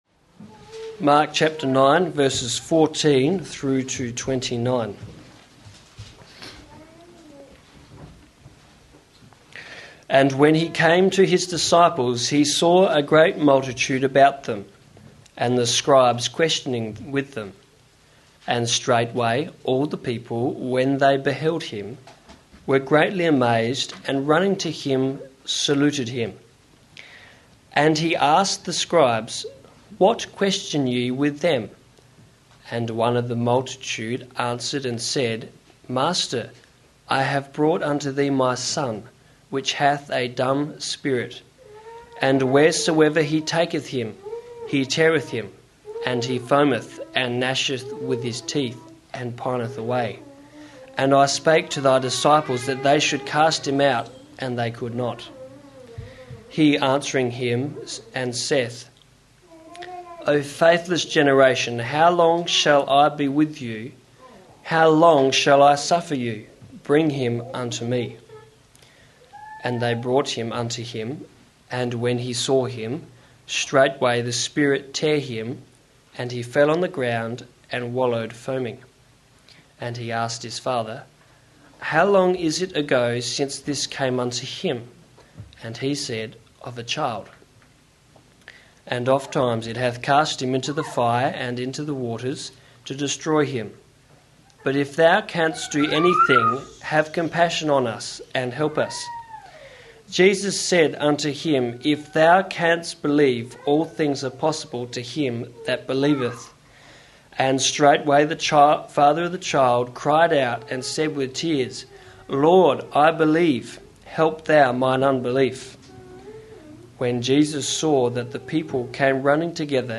Mark 9:14-29 Service Type: Father's Day Bible Text